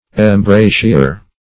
Embraceor \Em*brace"or\, n. (Law)